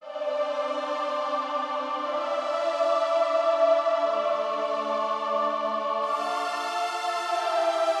Distant Apocalyptic Alarm
描述：A Alarm With Reverb
标签： Reverb Distant Apocalypse Alarm
声道立体声